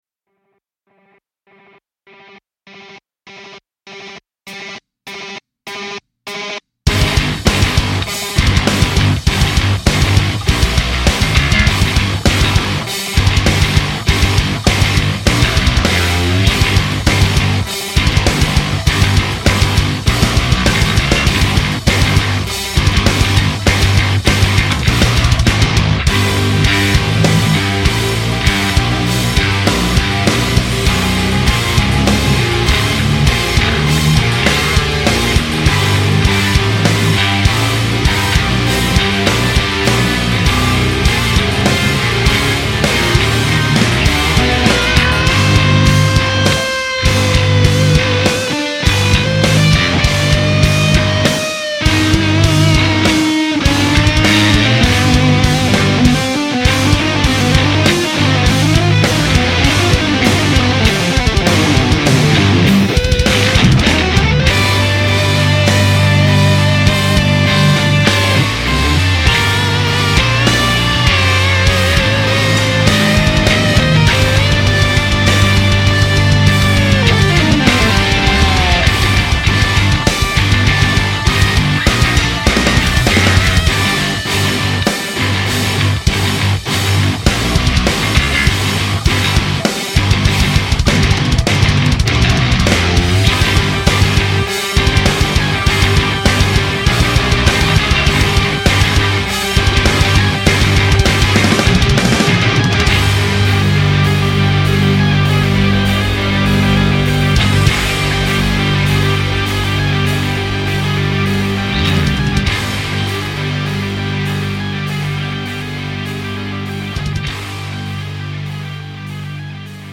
7弦重型吉他 Impact Soundworks Shreddage 3 Jupiter KONTAKT-音频fun
Shreddage 2的升级版，一把7弦重型吉他，声音丰富深沉，适合重摇滚和金属风格.
45种现成的音色快照，从高增益放大到清晰、空灵的演奏